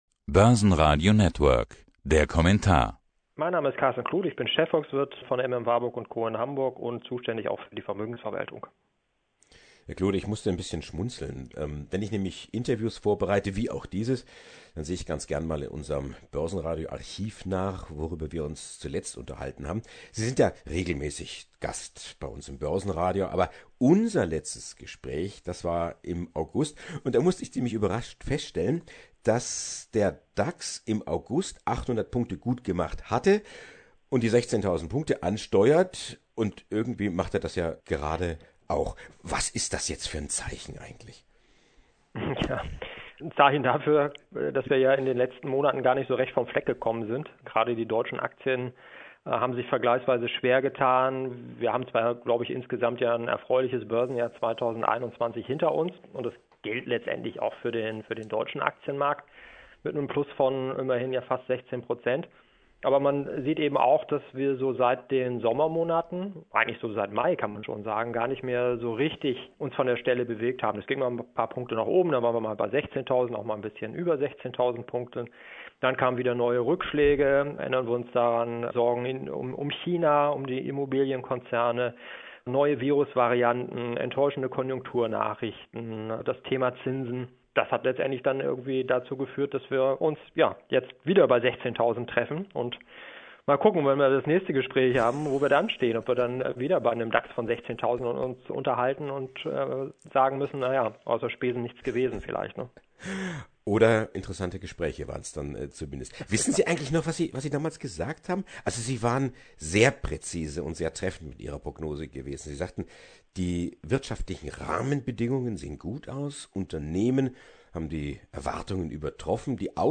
im Interview mit dem Börsen Radio.